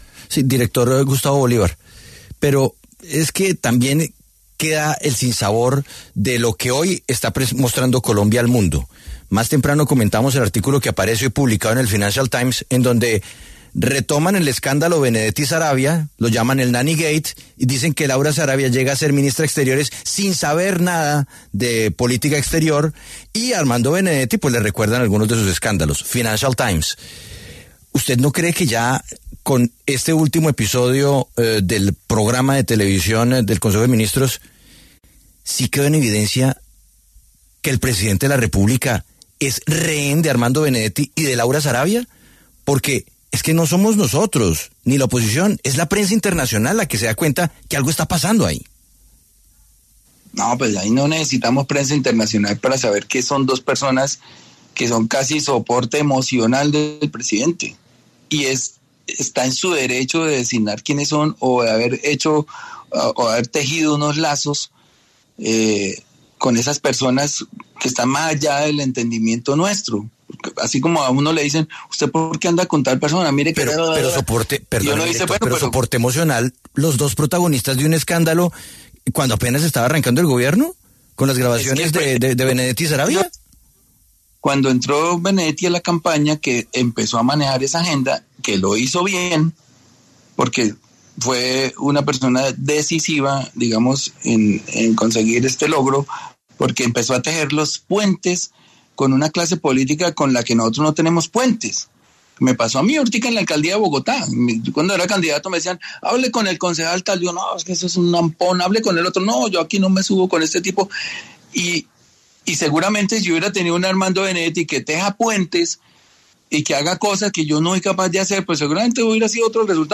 Gustavo Bolívar, director del Departamento para la Prosperidad Social, pasó por los micrófonos de La W para sobre el consejo de ministros que se llevó a cabo en la Casa de Nariño y que puso sobre la mesa las diferencias que hay dentro del gabinete del presidente Gustavo Petro.